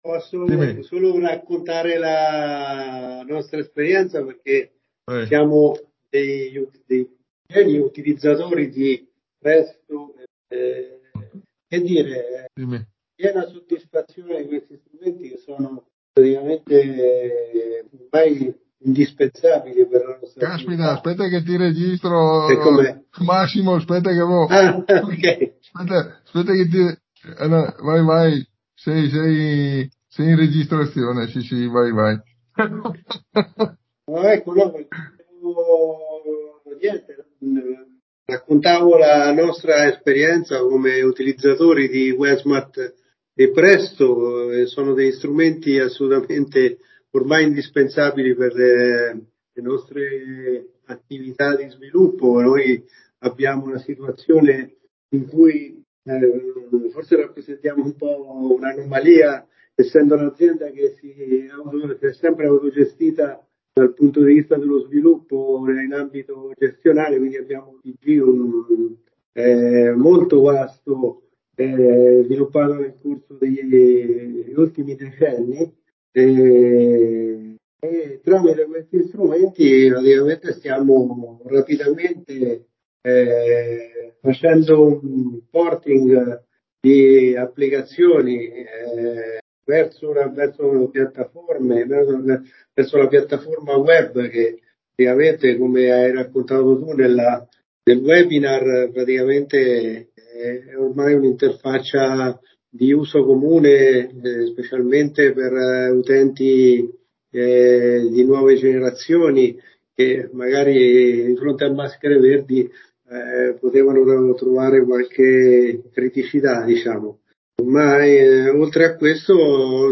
l'intervento spontaneo al termine del webinar